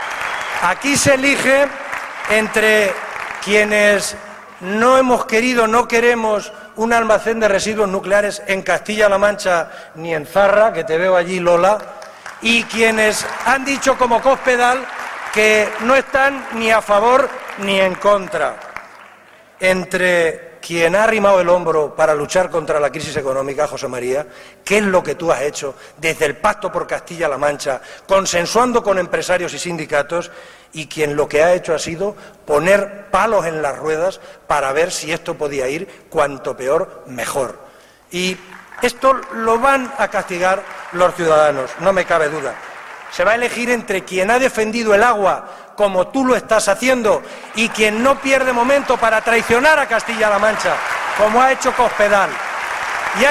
En el acto han participado más de 2.000 personas, que llenaron dos salas del Palacio de Congresos y Exposiciones de Albacete, entre los que había militantes y simpatizantes del partido, pero también representantes de la sociedad, de los empresarios, los sindicatos, de los vecinos, de las asociaciones socio sanitarias, que recibieron un aplauso del público, por ser compañeros de viaje en el trabajo por Albacete y Castilla-La Mancha.